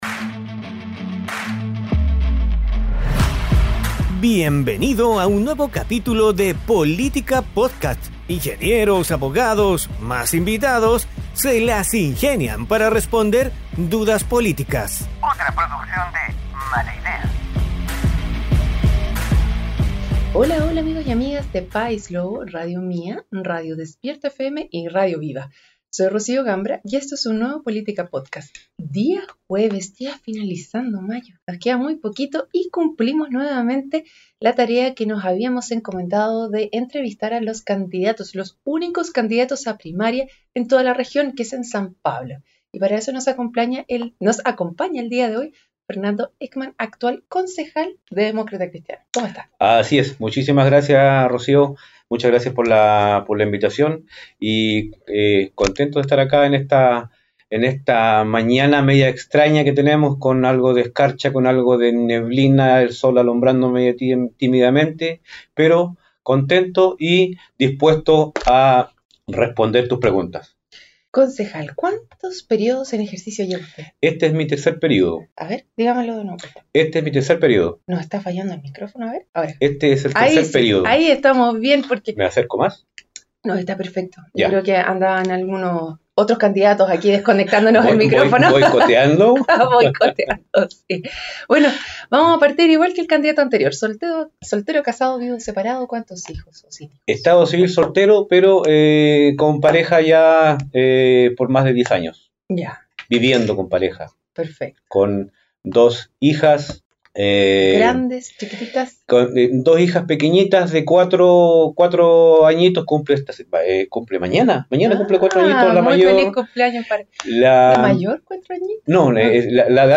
se entrevista a Fernando Heckmann, concejal de San Pablo y candidato a la alcaldía. Durante la conversación, Heckmann habla sobre su trayectoria política, su vida personal, los desafíos actuales de la comuna y sus propuestas para mejorar la gestión municipal y educativa.